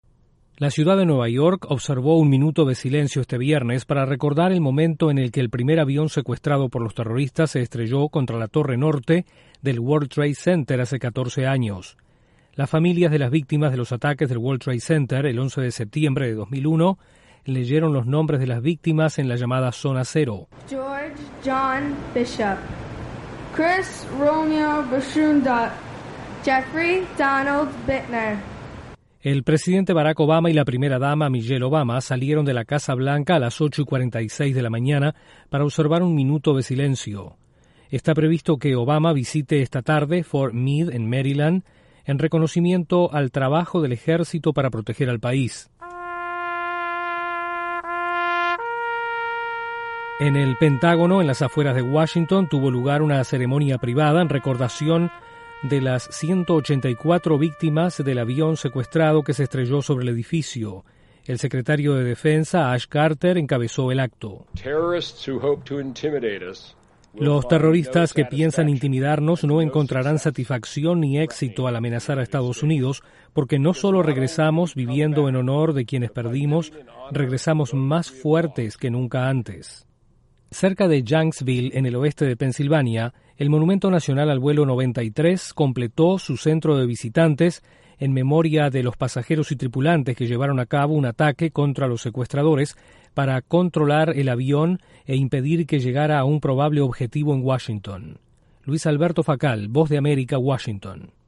Con diferentes actos se recuerdan en EE.UU. los ataques terroristas del 11 de septiembre de 2001. Desde la Voz de América en Washington informa